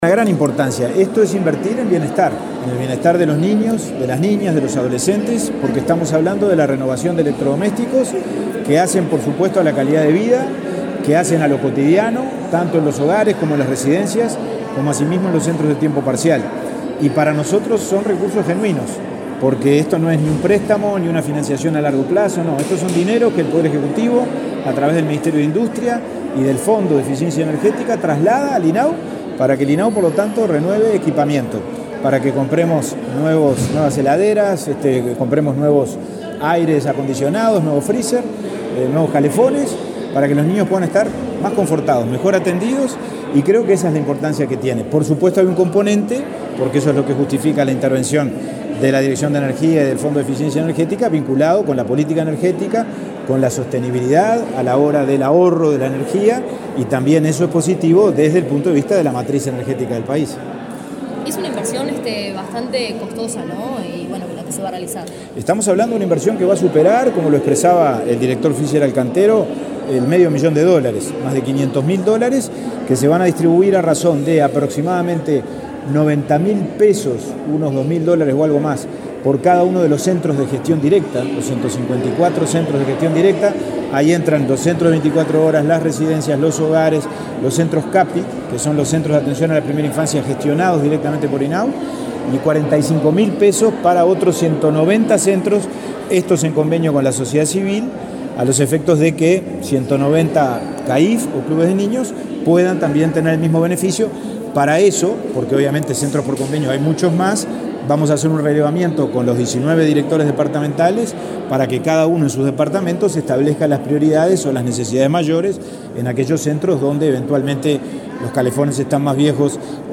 Declaraciones del presidente del INAU, Pablo Abdala
Declaraciones del presidente del INAU, Pablo Abdala 31/10/2022 Compartir Facebook X Copiar enlace WhatsApp LinkedIn El presidente del Instituto del Niño y el Adolescente del Uruguay (INAU), Pablo Abdala, firmó un convenio con representantes de la Dirección Nacional de Energía y luego dialogó con la prensa.